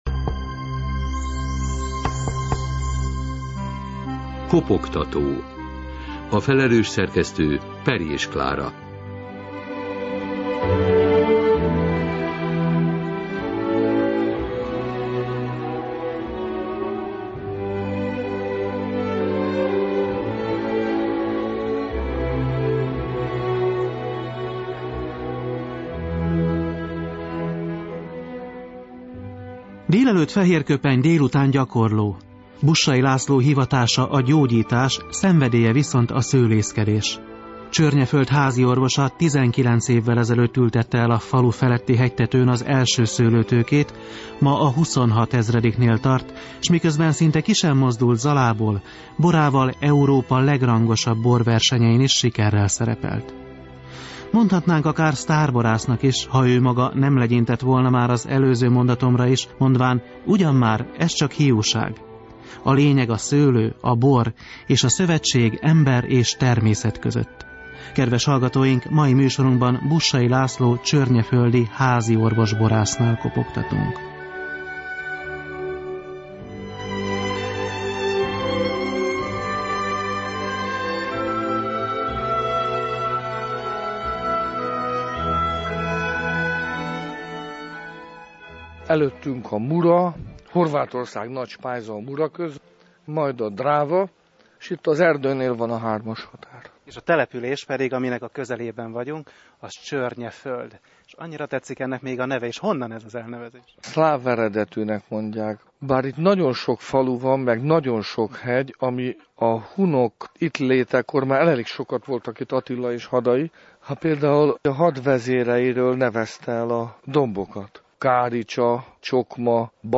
Kopogtató - rádióinterjú